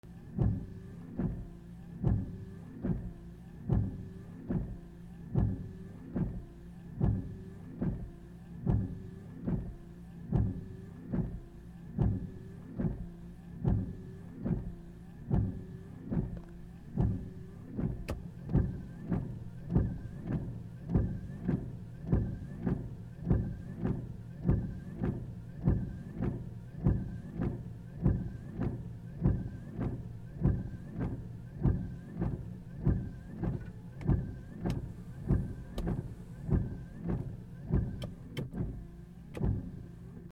ワイパー(速度いろいろ)
/ E｜乗り物 / E-10 ｜自動車 / ファンカーゴ
H4n